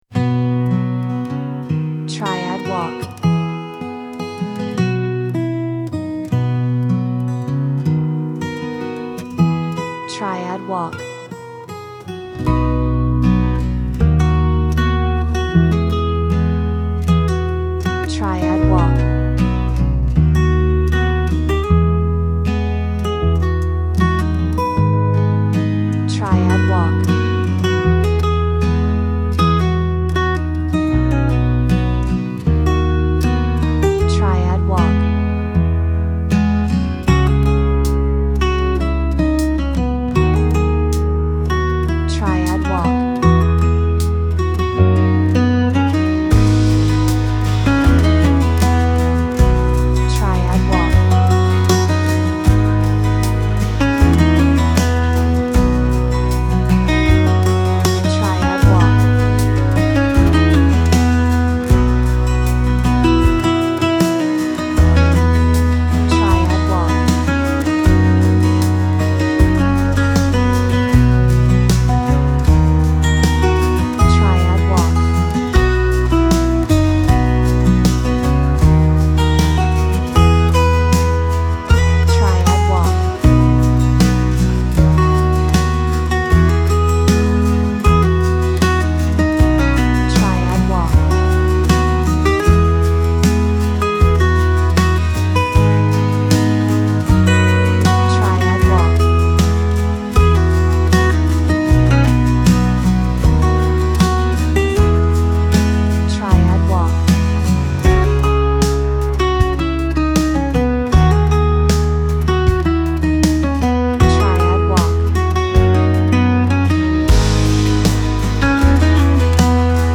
温かみのあるギターの音色が特徴です。心地よく、聴きやすい音楽に仕上がっています。
Folk Song , Solo guitar